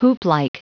Prononciation du mot hooplike en anglais (fichier audio)
hooplike.wav